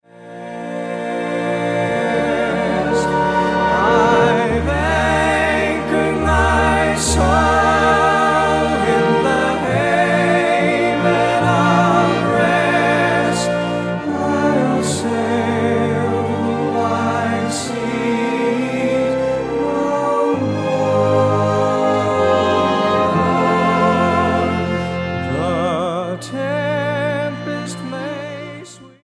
STYLE: Southern Gospel
tender harmonies